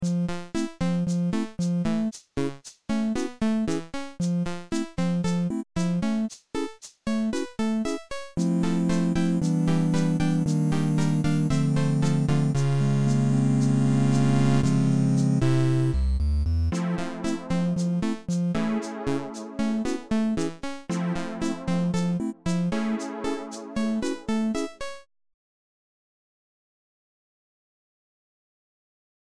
i'm glad you asked! it's an online sequencer/DAW thing where you can make simple loops or full songs. it's completely free and online you can find it here. i promise im not sponsored or anything i just really like this website since i'm too lazy to actually learn a music program like FL studio LOL. actually, beepbox is the original website, but i use jummbox cause i think it has a lot more features than beepbox. there are like a million different beepbox variations out there (because beepbox is open source) and theres a discord too if you want to check all of them out